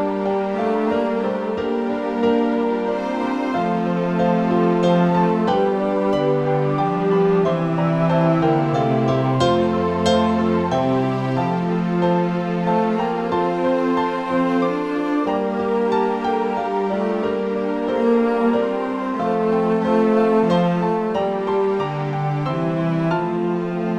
Christmas Carol